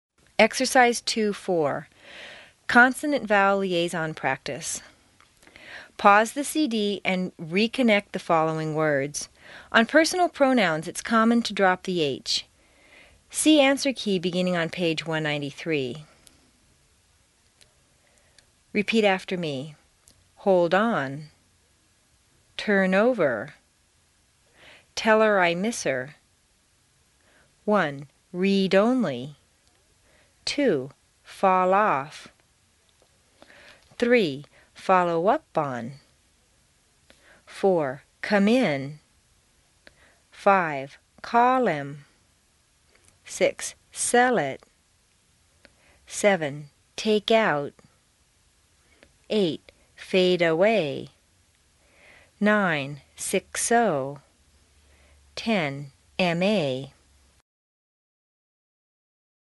美语口音训练第二册39 听力文件下载—在线英语听力室